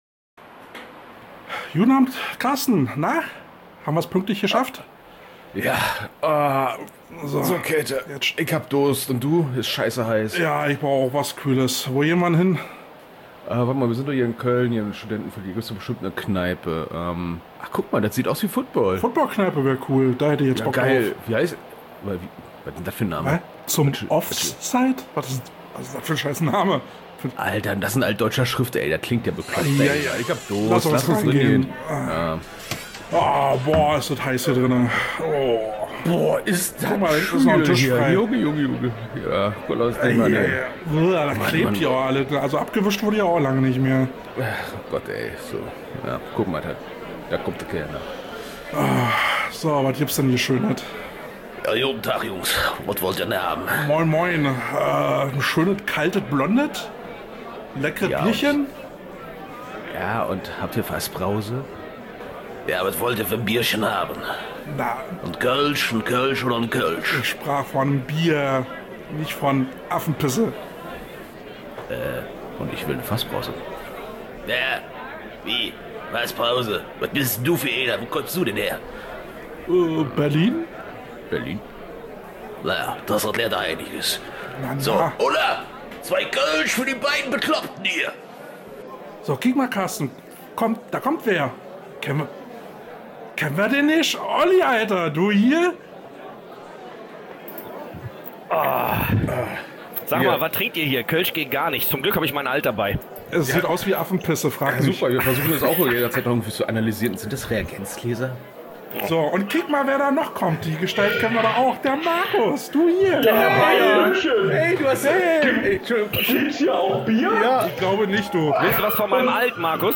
Fünf Footballverrückte finden sich zusammen, um miteinander alte Geschichten auszutauschen und über die aktuellen Geschehnisse im Football zu sprechen.